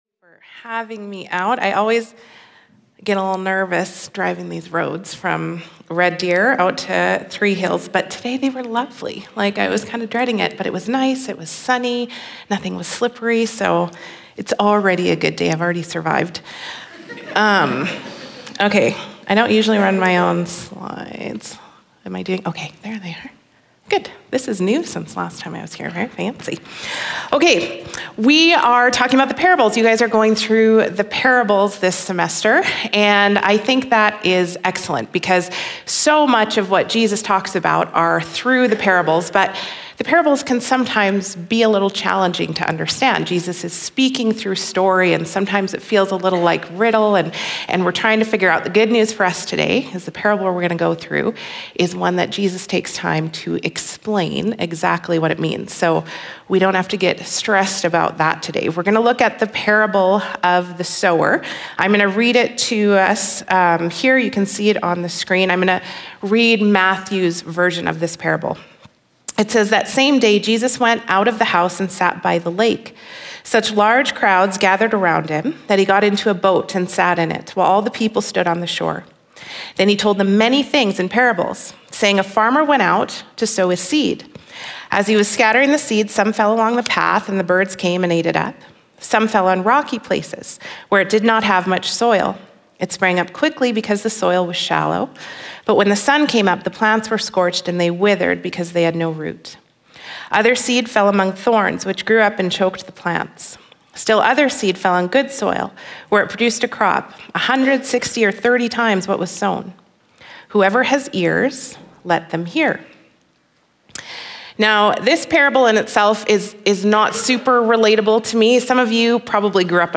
We welcome you to join us every week for a new Community Chapel service here at Prairie College.